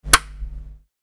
Noisy Switch